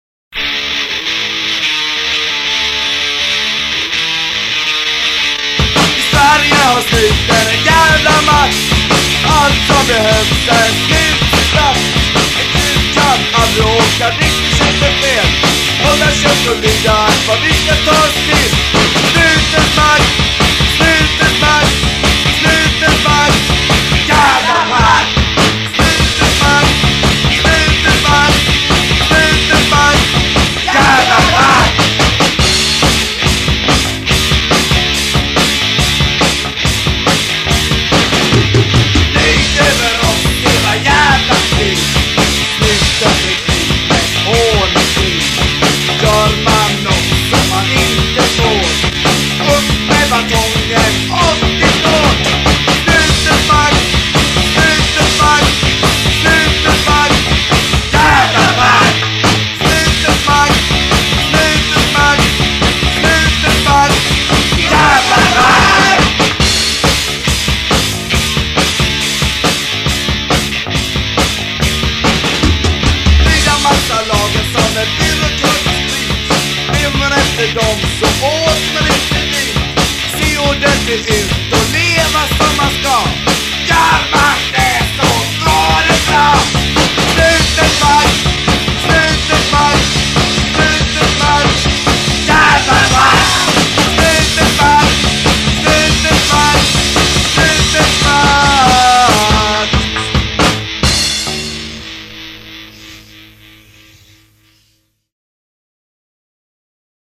punkband